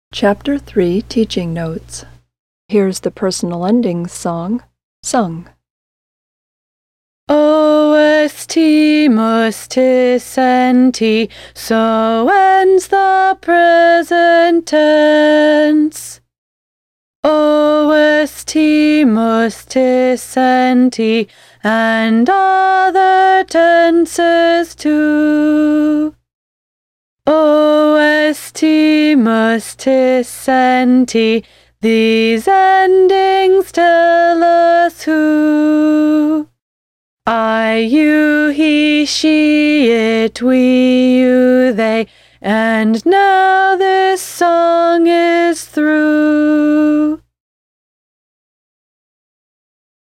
Put the present tense endings in order as they are written in a verb box. You can listen to the following personal endings song to help you, if you can't remember the order.